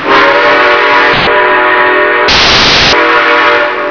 AMERICAN WHISTLES
sp6chime.wav